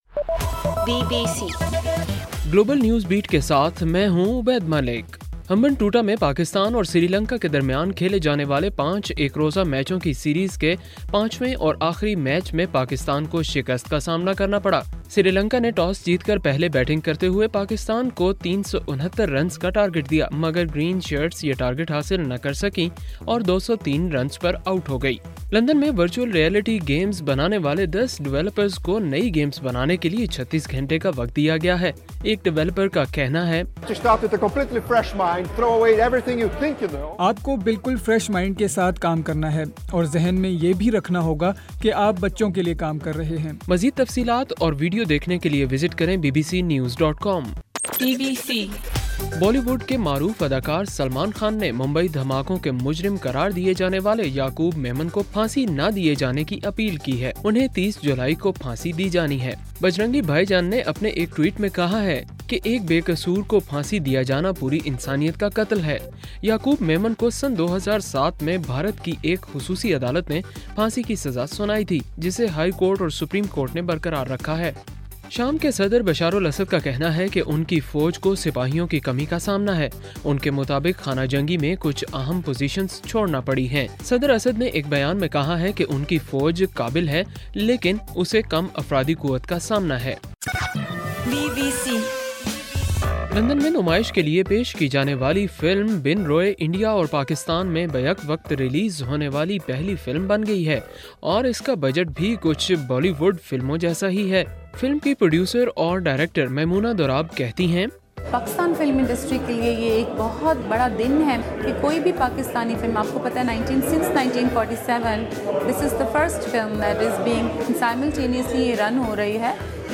جولائی 26: رات 11 بجے کا گلوبل نیوز بیٹ بُلیٹن